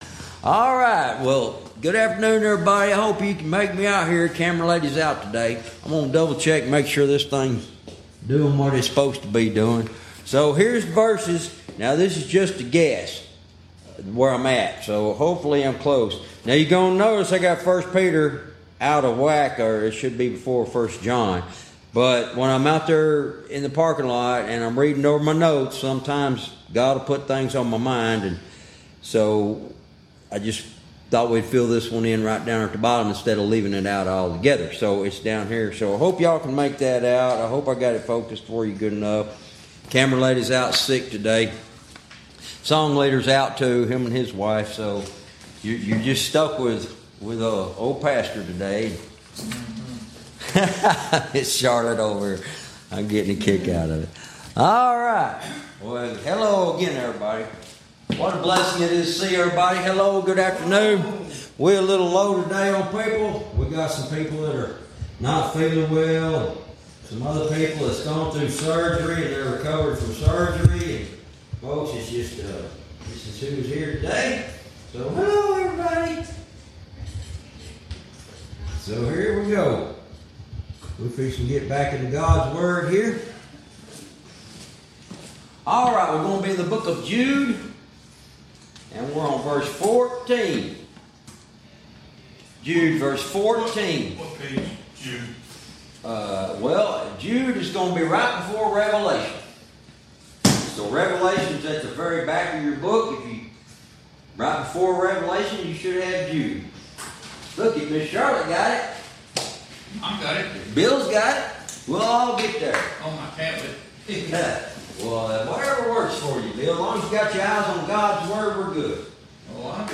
Verse by verse teaching - Jude Lesson 59 Verse 14